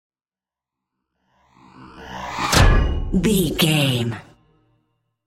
Sci fi whoosh to hit
Sound Effects
dark
futuristic
intense
tension
woosh to hit